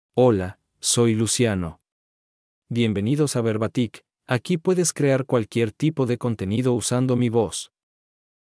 Luciano — Male Spanish (Mexico) AI Voice | TTS, Voice Cloning & Video | Verbatik AI
Luciano is a male AI voice for Spanish (Mexico).
Voice sample
Listen to Luciano's male Spanish voice.
Luciano delivers clear pronunciation with authentic Mexico Spanish intonation, making your content sound professionally produced.